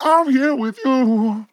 FORM im here with you Vocal Sample
Categories: Vocals
LOFI VIBES, LYRICS, male, sample, wet
MAN-LYRICS-FILLS-120bpm-Am-9.wav